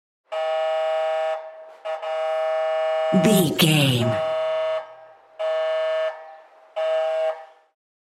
Ambulance Ext Horn Distant
Sound Effects
urban
chaotic
emergency